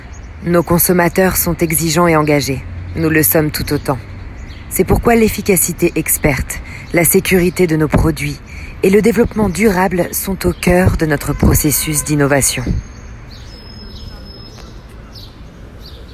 Voix off
20 - 40 ans - Mezzo-soprano
voix-grave